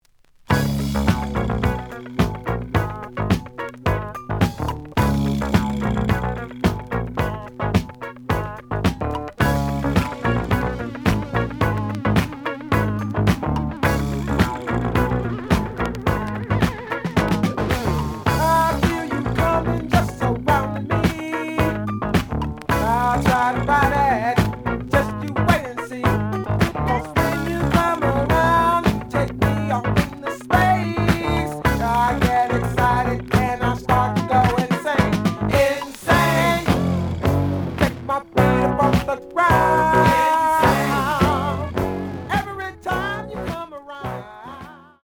The audio sample is recorded from the actual item.
●Genre: Funk, 70's Funk
Some click noise on last of A side due to scratches.